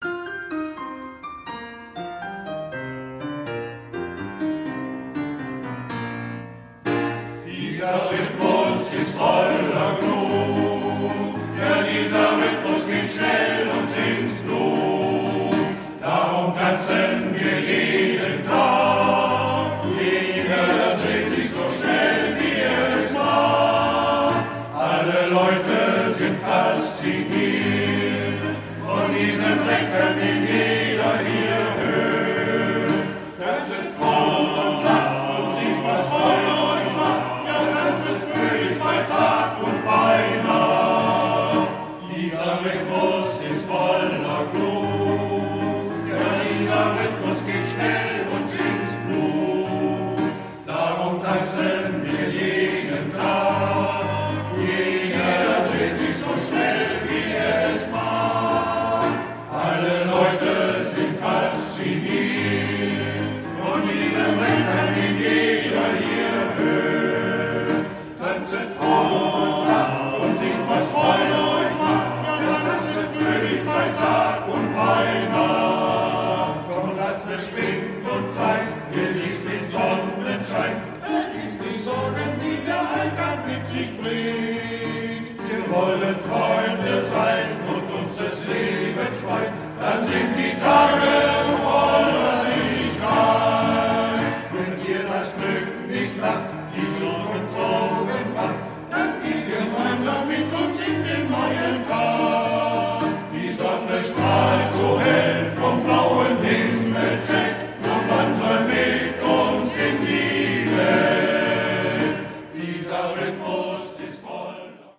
Uslar Aufnahmeort: St. Jacobi-Kirche Salzderhelden